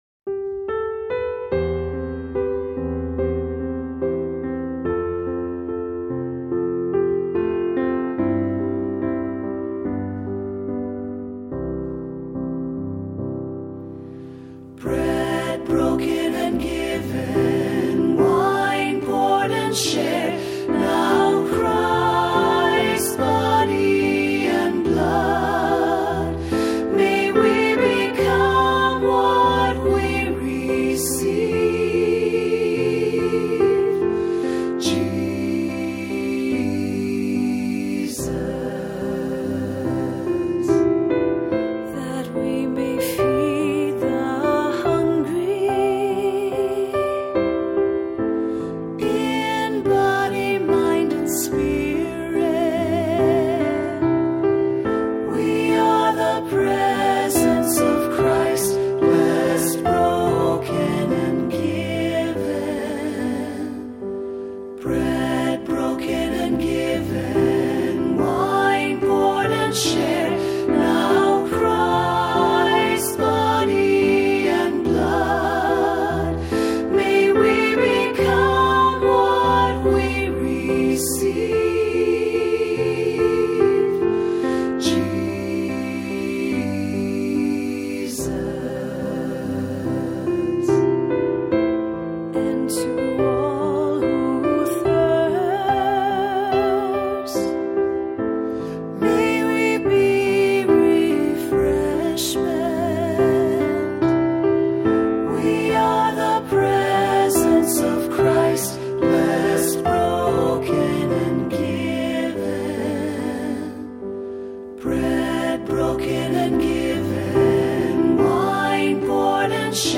Voicing: SATB, cantor, assembly